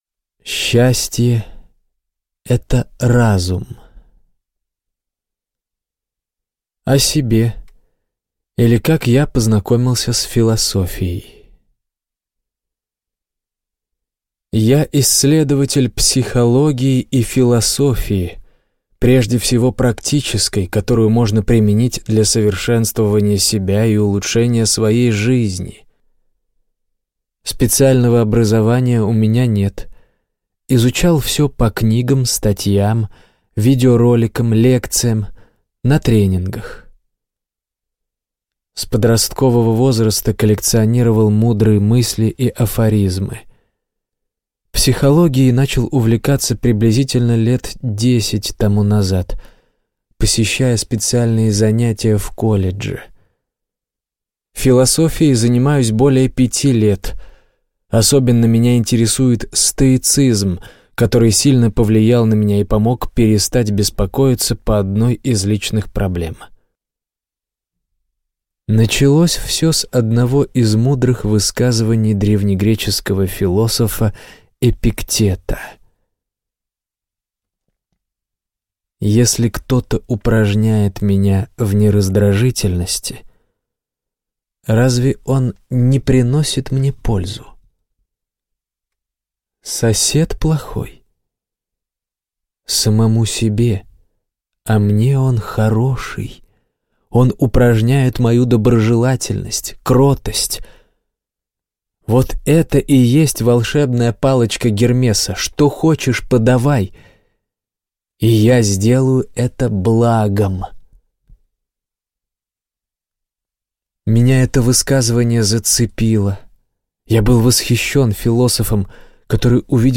Аудиокнига Счастье – это разум | Библиотека аудиокниг